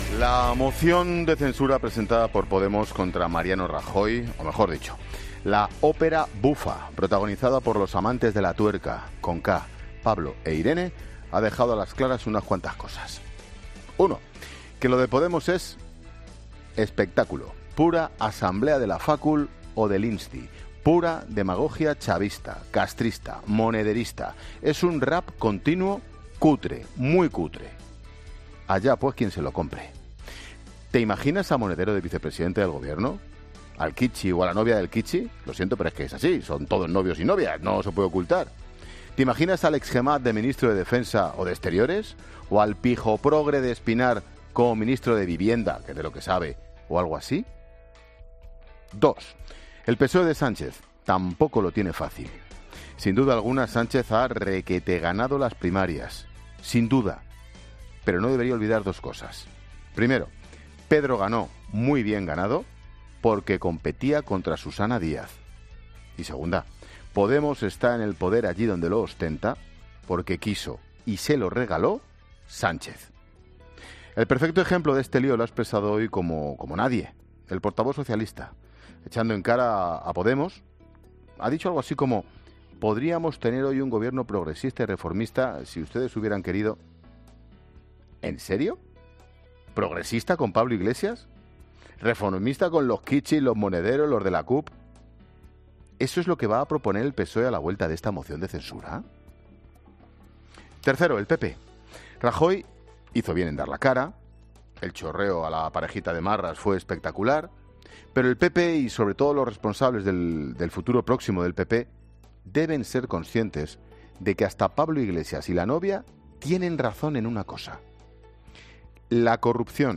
AUDIO: Monólogo de Ángel Expósito a las 16h. una vez rechazada la moción de censura contra Mariano Rajoy presentada por Podemos.